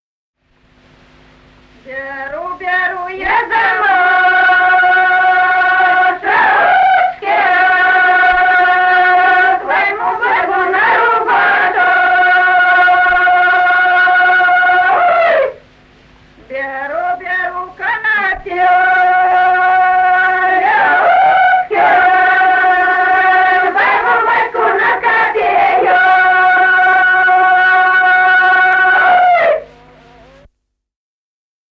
Народные песни Стародубского района «Беру, беру я замашечки», прополочная.